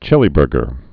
(chĭlē-bûrgər)